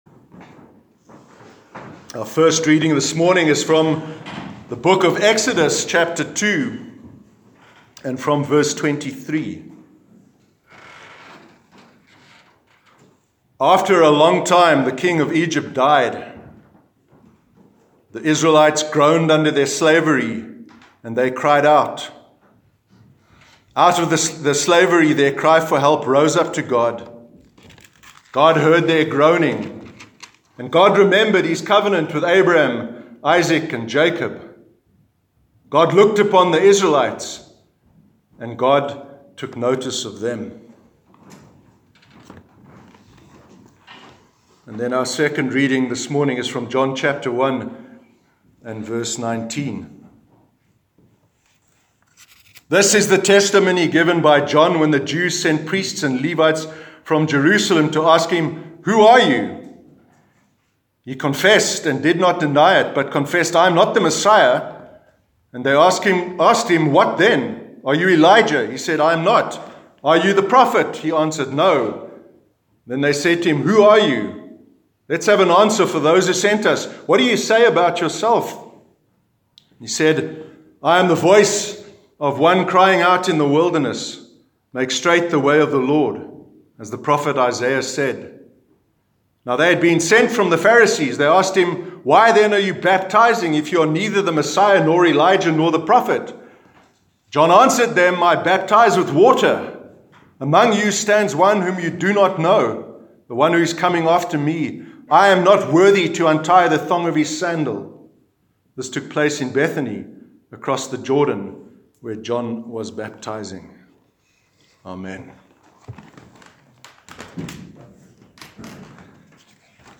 sermon-9-december-2018.mp3